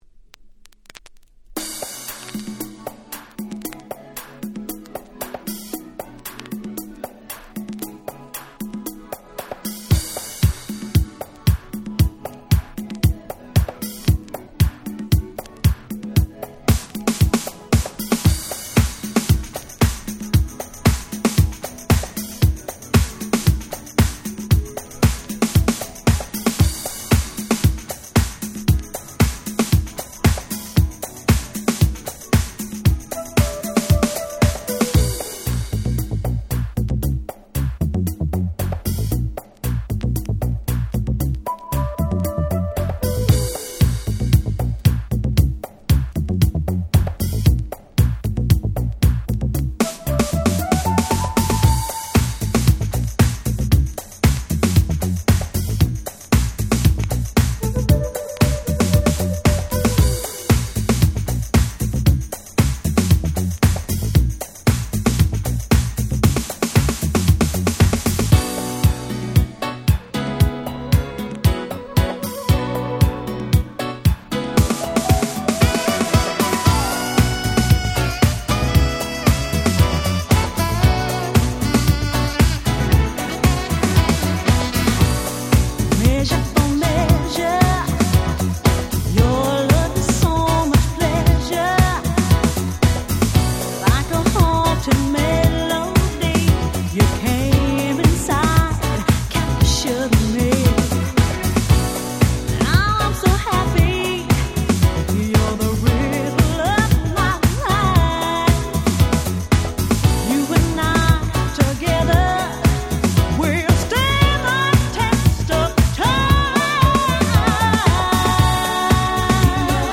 91' UK R&B Classic !!!!!